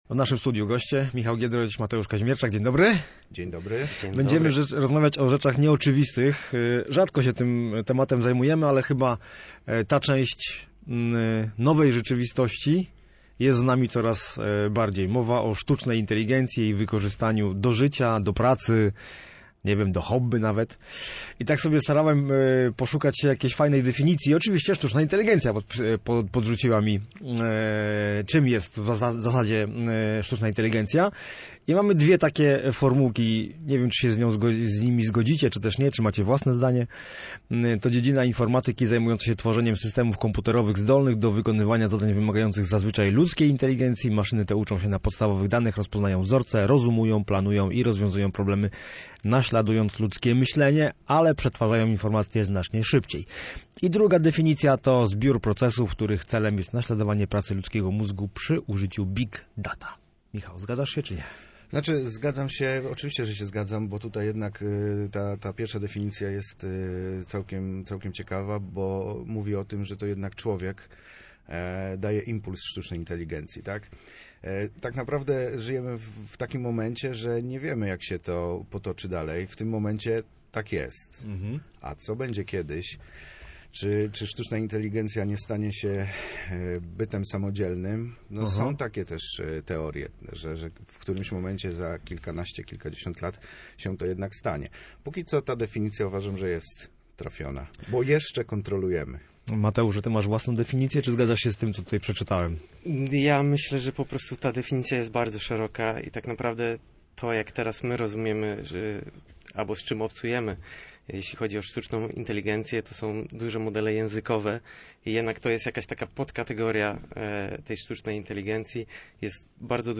Rozmowa o AI w Studiu Słupsk